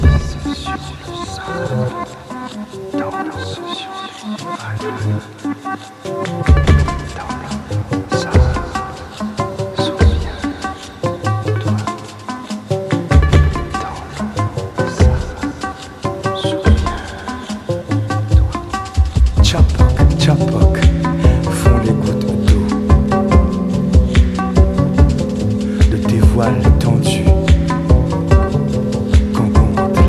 électro maloya